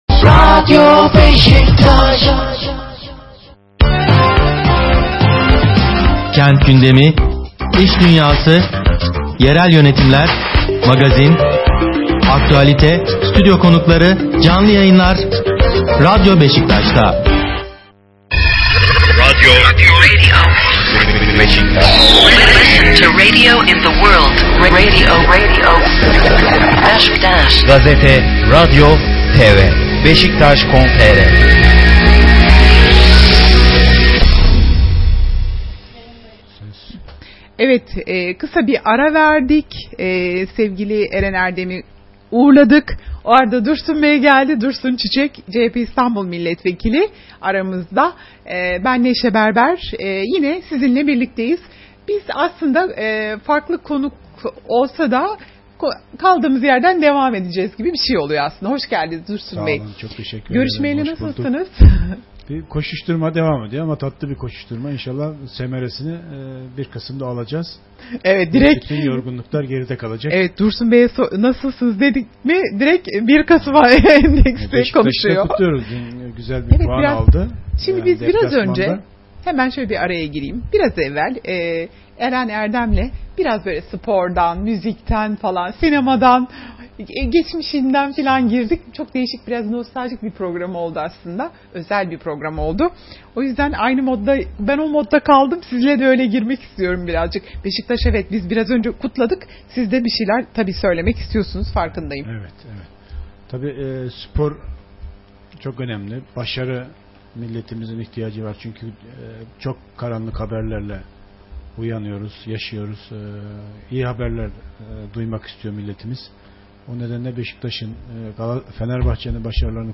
YAYIN TEKRARI: Dursun Çiçek Radyo Beşiktaş’a konuştu